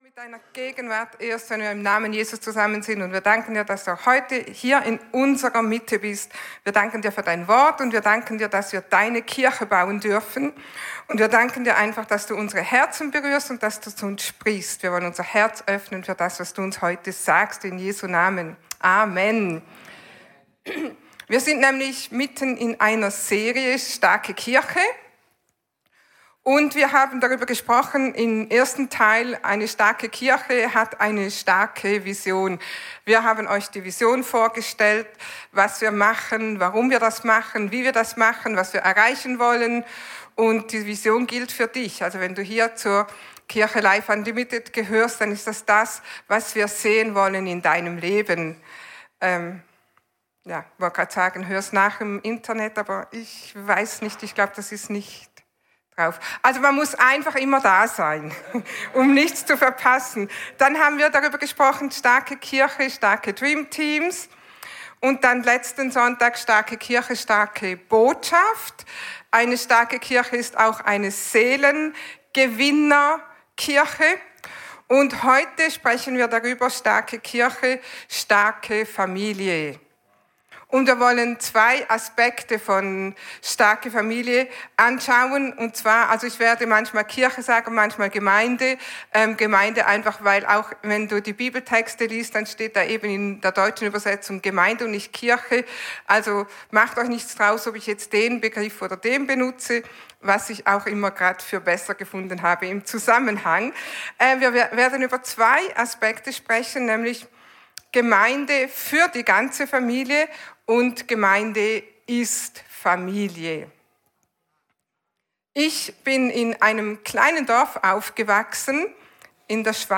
Alle Predigten aus den Sonntagsgottesdiensten